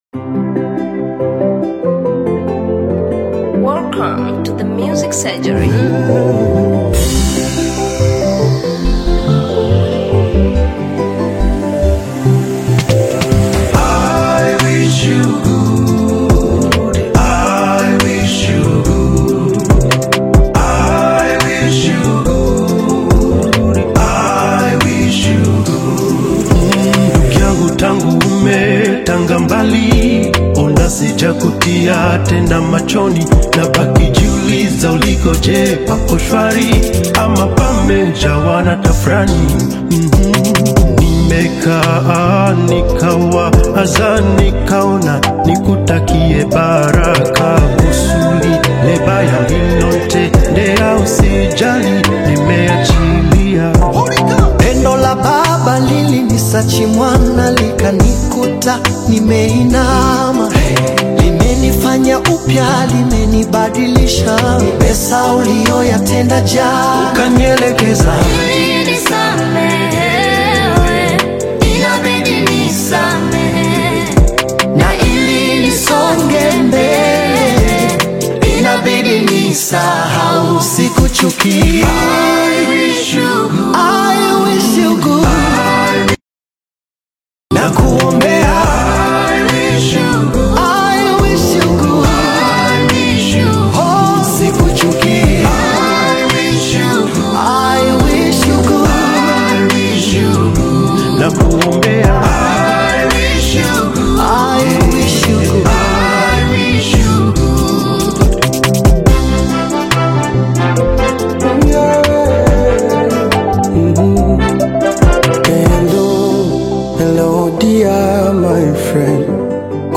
AudioGospel